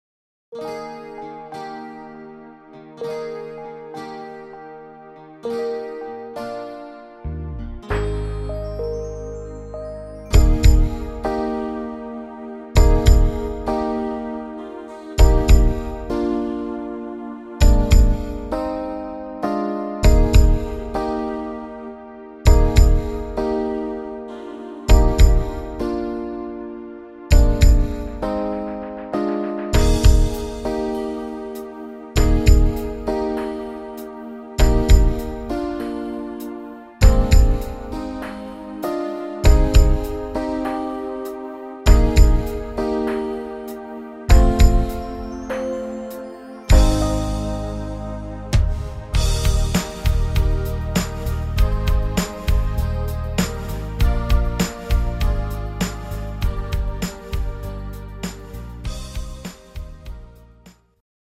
Rhythmus  Medium Beat
Art  Pop, Englisch